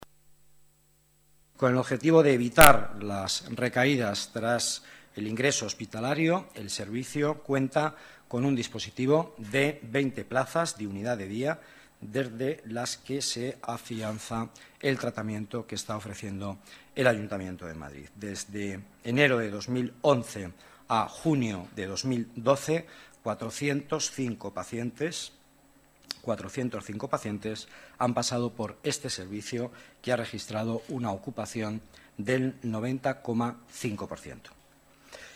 Nueva ventana:Declaraciones del vicealcalde, Miguel Ángel Villanueva: atención parcientes patología dual